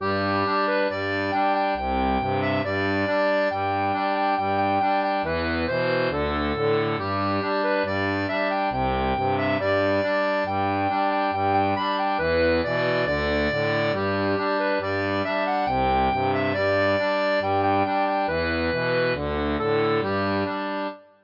• une version pour accordéon diatonique à 2 rangs
Chanson française